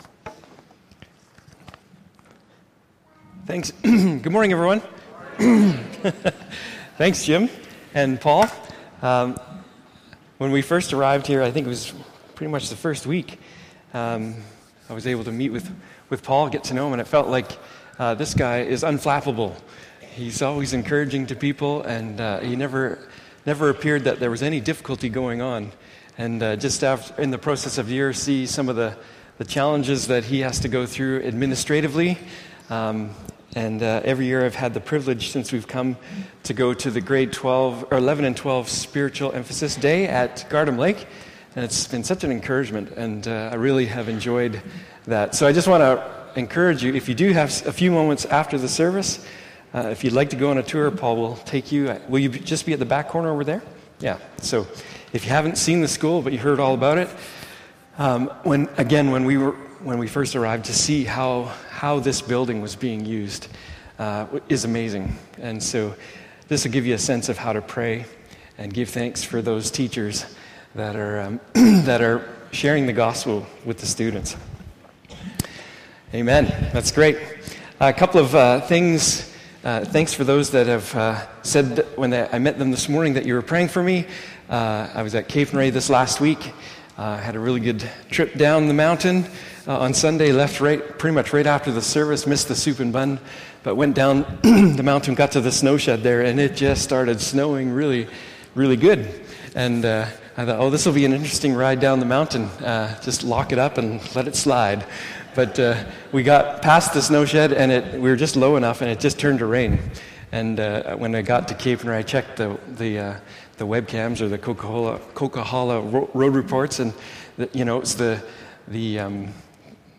Passage: Mark: 8;31-9;13 Service Type: Morning Service